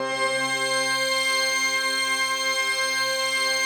PAD 46-5.wav